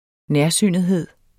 Udtale [ ˈnεɐ̯ˌsyˀnəðˌheðˀ ]